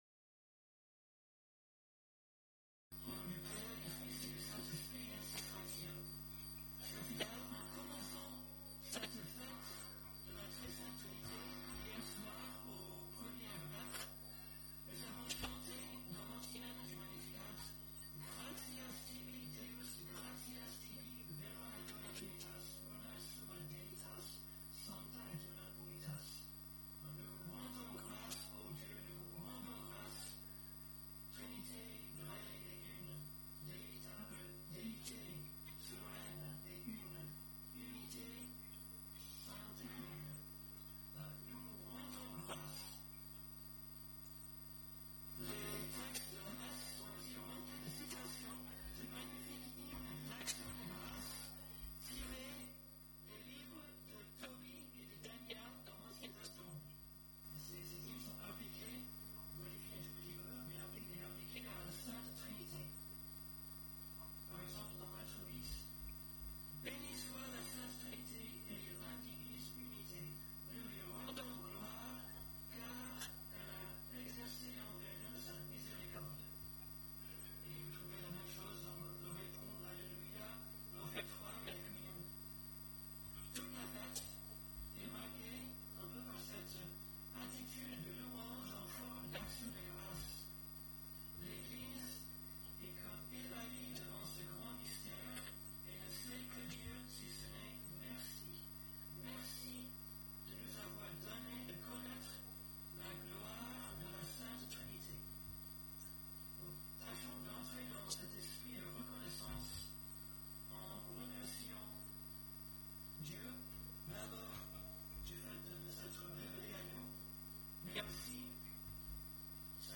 Occasion: Fête de la Très Sainte Trinité
Type: Sermons